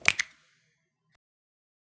sub_menu_btn.73be99a3.mp3